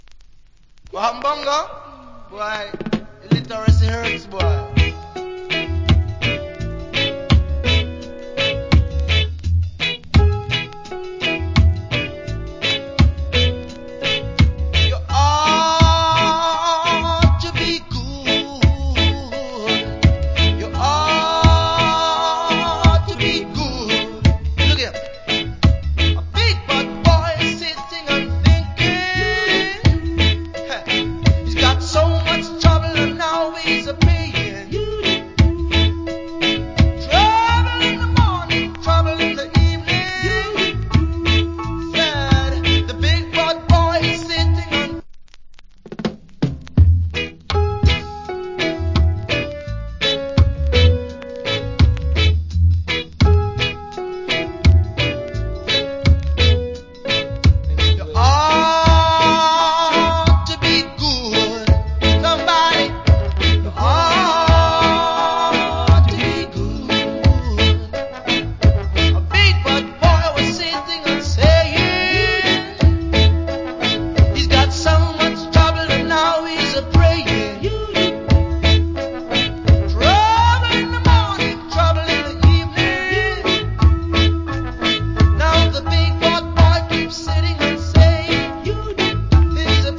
Nice Reggae Vocal.